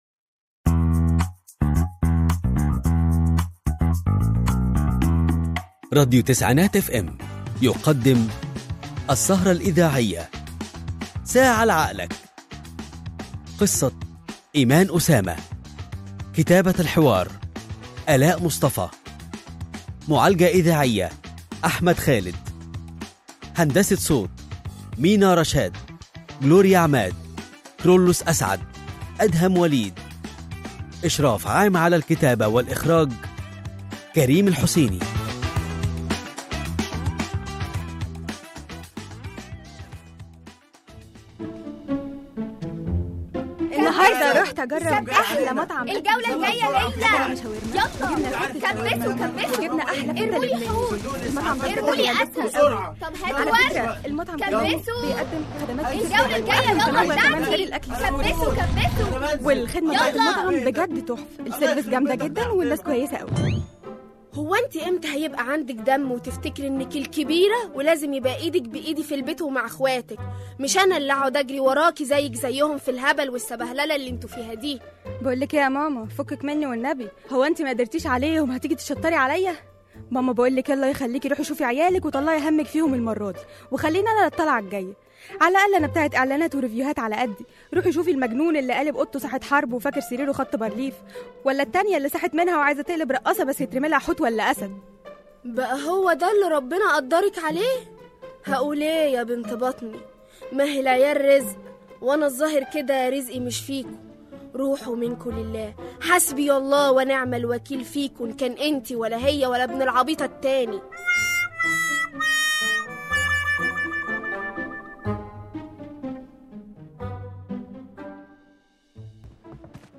الاسكتش الإذاعي ساعة لعقلك